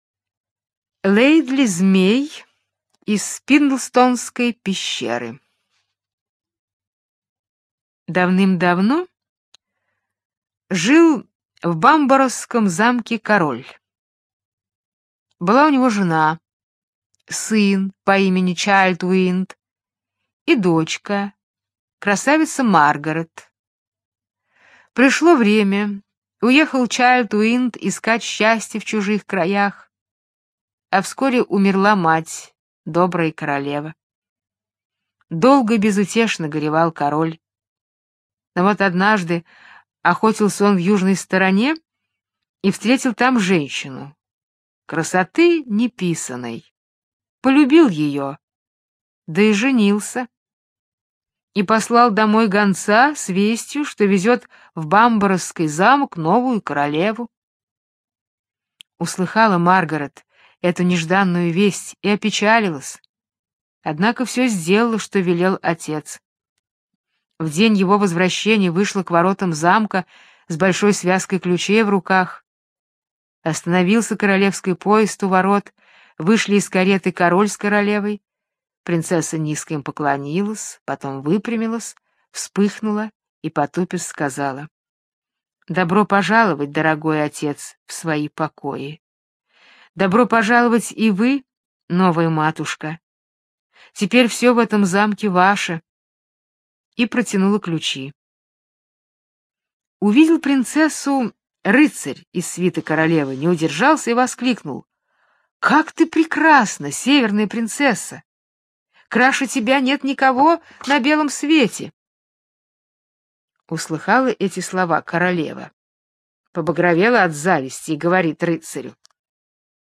Слушайте Лэйдли-змей из Спиндлстонской пещеры - британская аудиосказка.